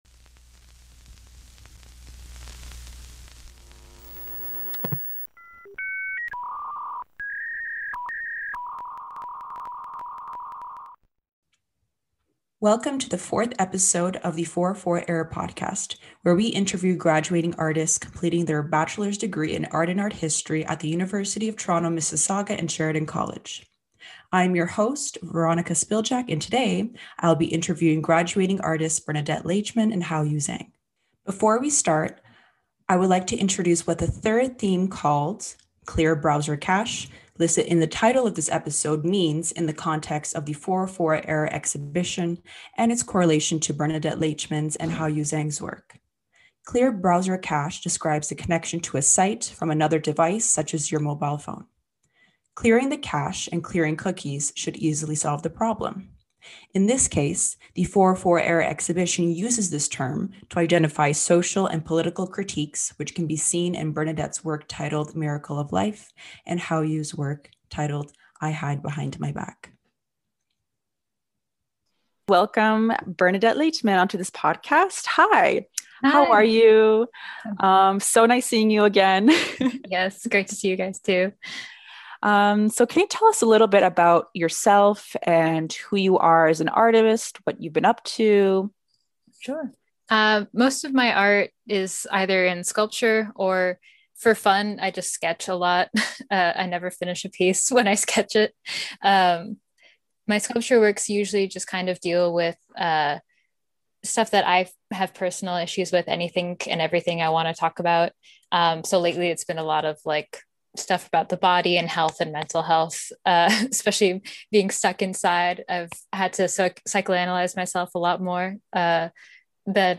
Throughout this podcast series, we’ll be interviewing graduating artists completing their bachelor’s degrees in Art and Art History in the joint University of Toronto Mississauga and Sheridan College program.